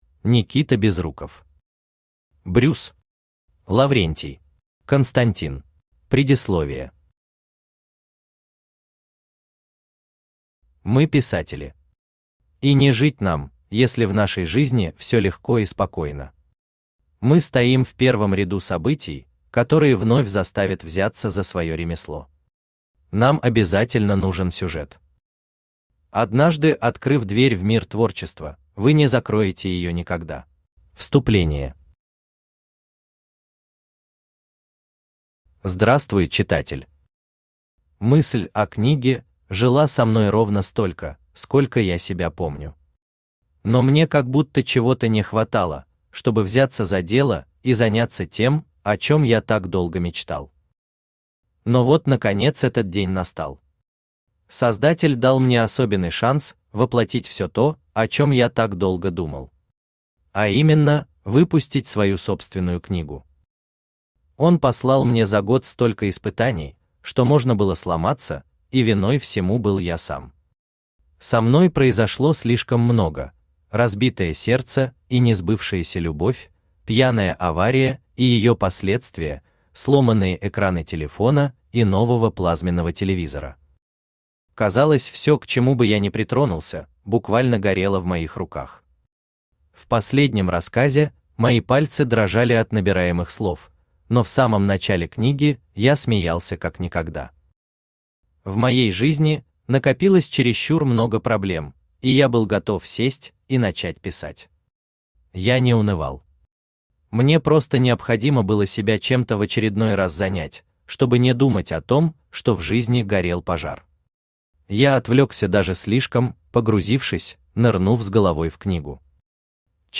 Аудиокнига Брюс. Лаврентий. Константин | Библиотека аудиокниг
Константин Автор Никита Безруков Читает аудиокнигу Авточтец ЛитРес.